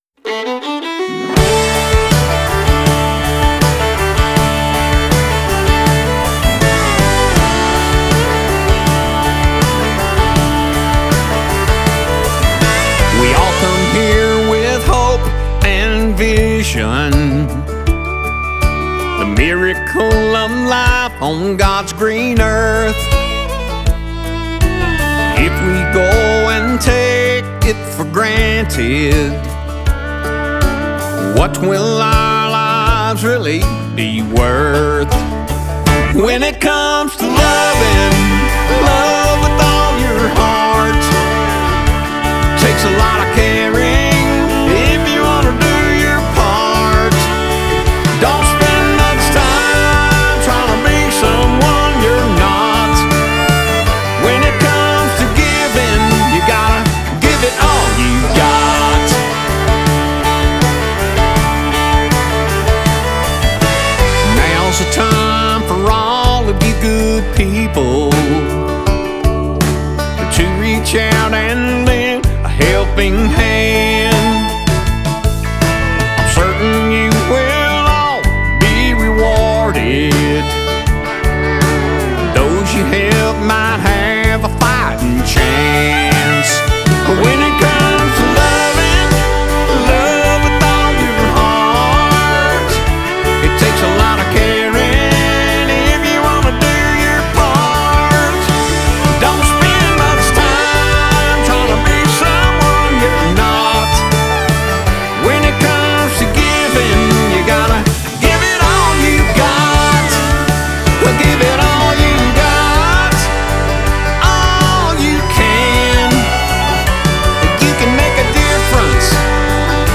SONG GENRE – Country
With its driving melody and emotionally charged vocal